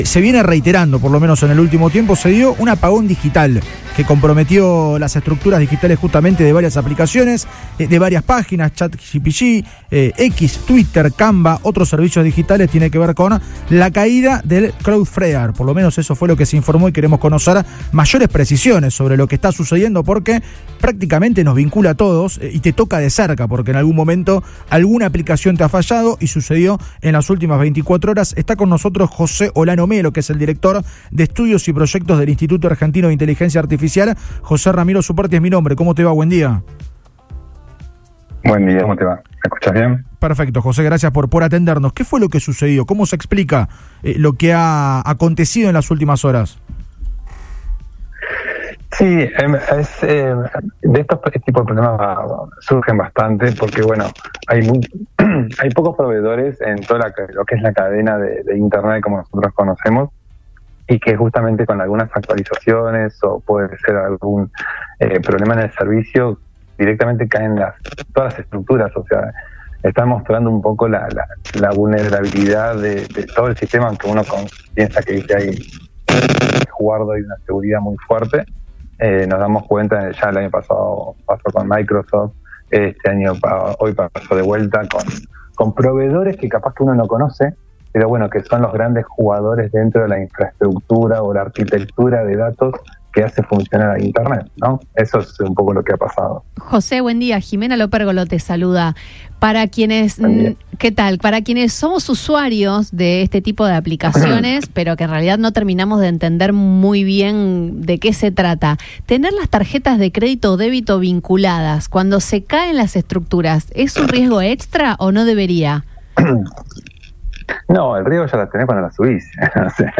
EN FM RIO 96.9